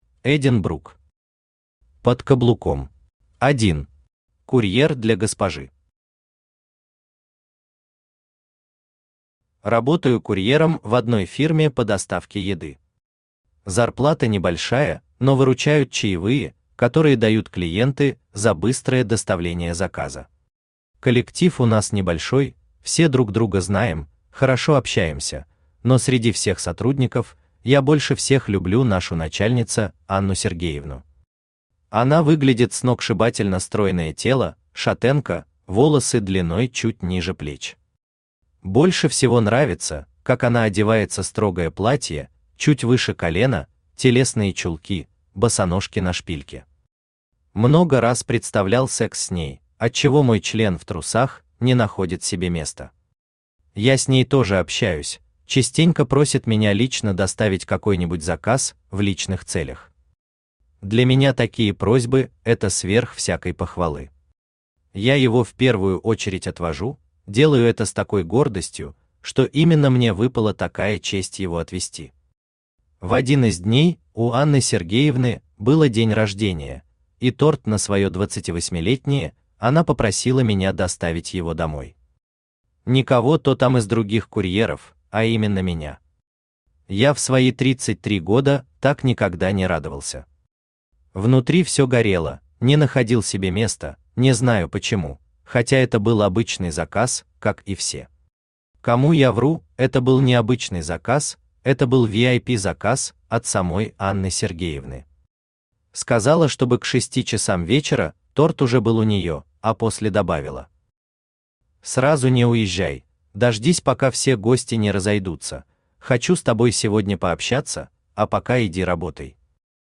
Aудиокнига Под каблуком Автор Эдин Брук Читает аудиокнигу Авточтец ЛитРес.